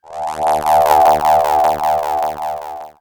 Hum19.wav